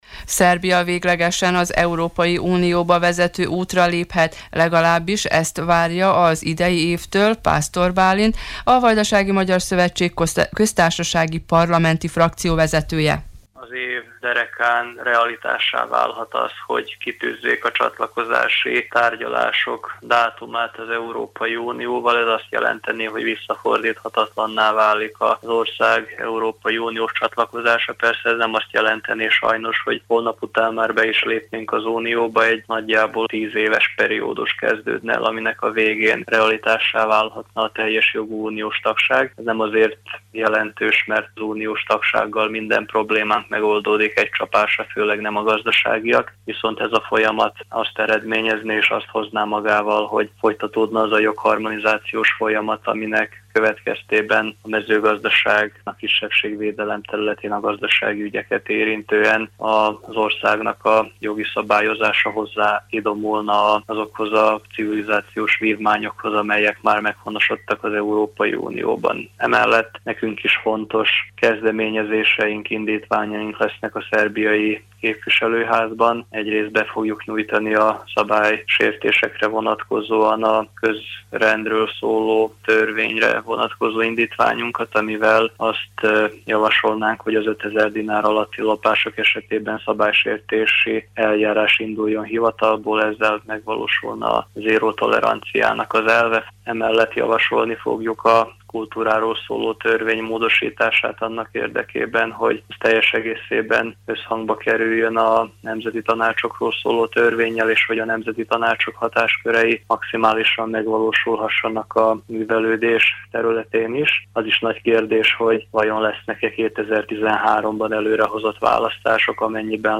Híradó - A Kormány első 100 napjának értékelése